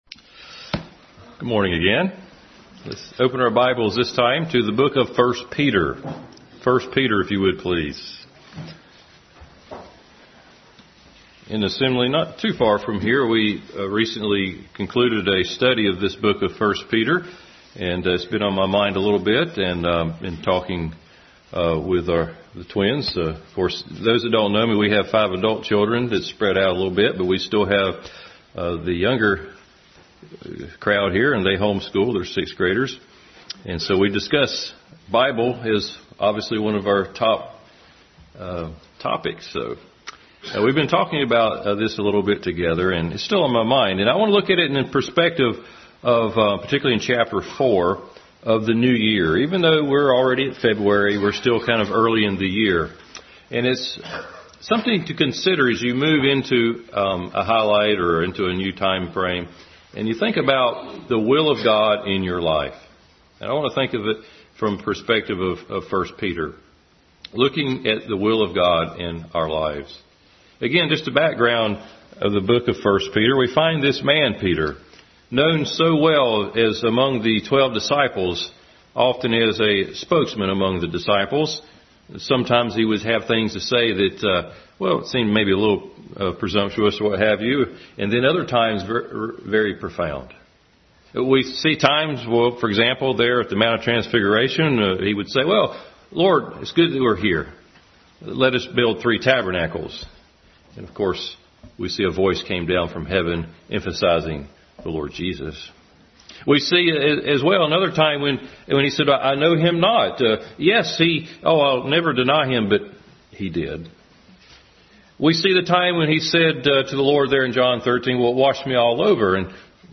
The Will of God In Our Lives Passage: 1 Peter 4:1-19 Service Type: Family Bible Hour Bible Text